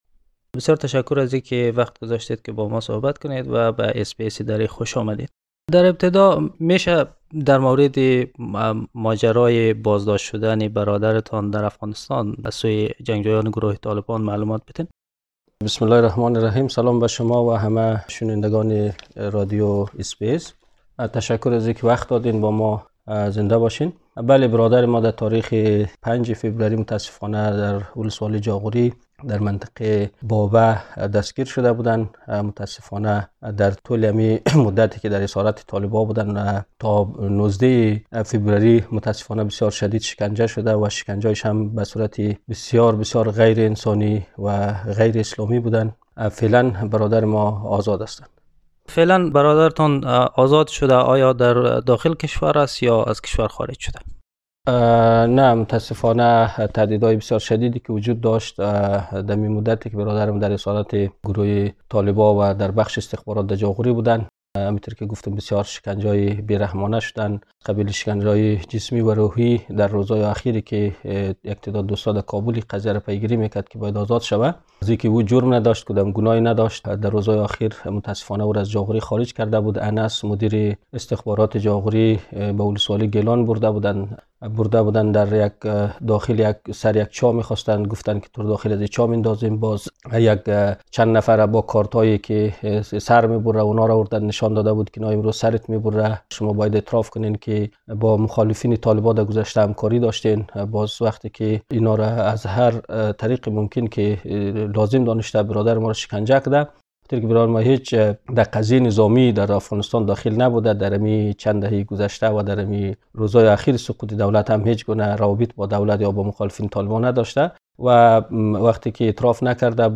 The interview is in the Dari language